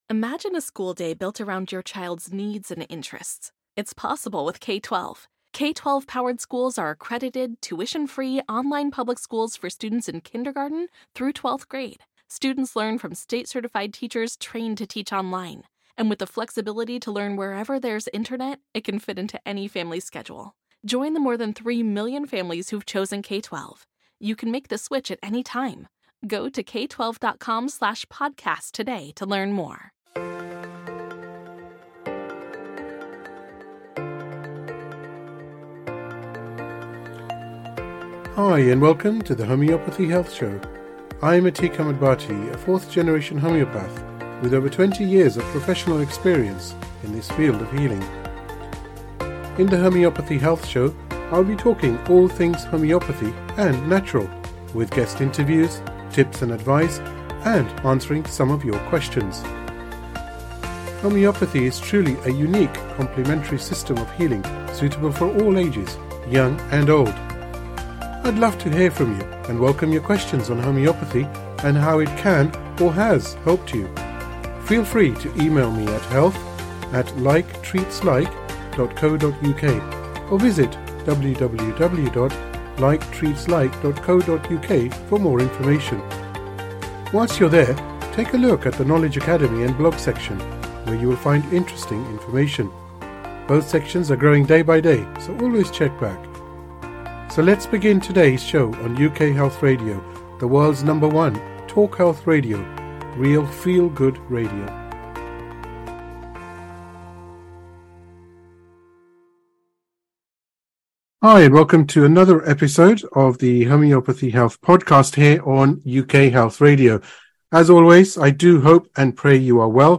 coupled with his deadpan humour